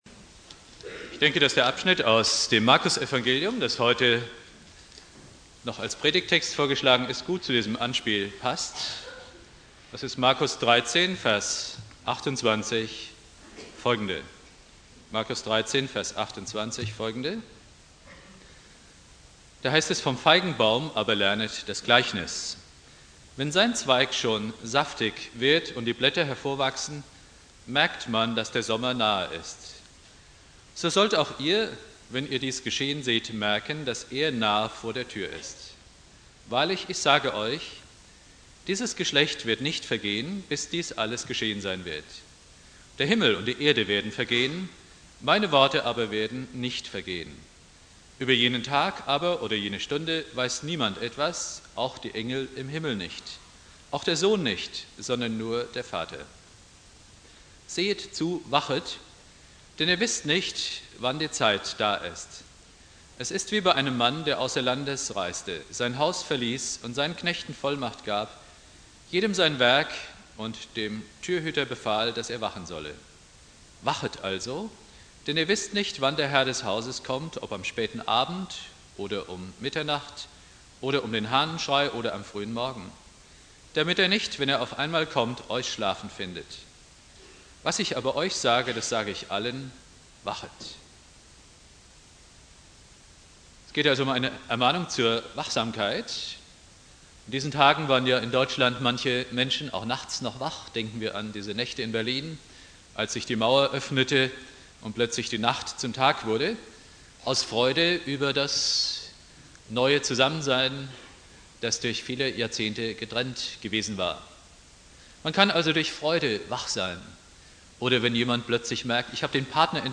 Predigt
Ewigkeitssonntag